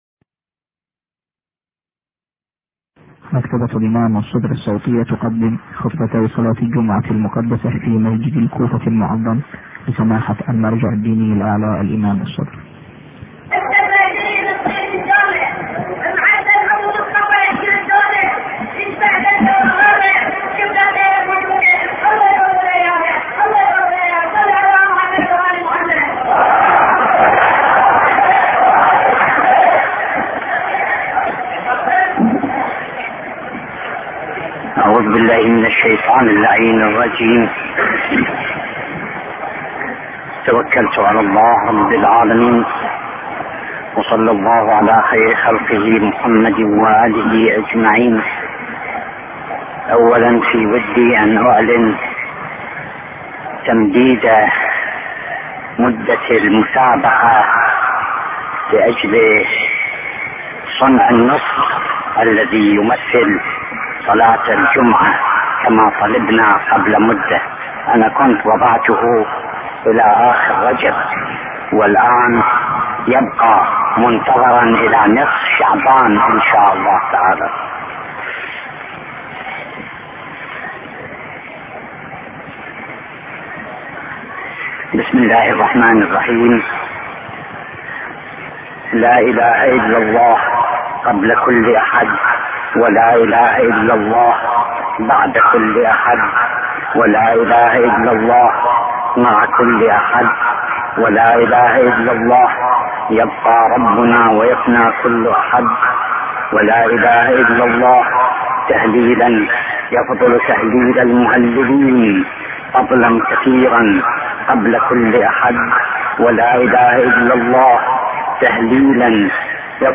خطبتي صلاة الجمعة المباركة لسماحة اية الله العظمى السيد الشهيد محمد محمد صادق الصدر والتي القيت في مسجد الكوفة المعظم .